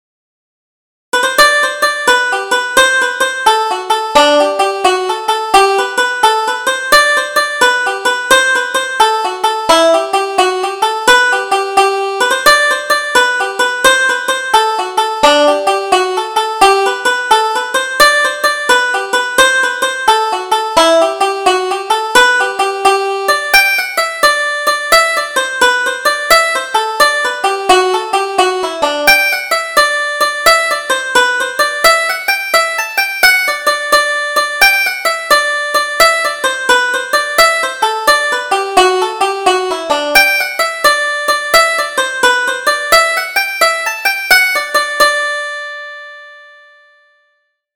Double Jig: A Trip to the Cottage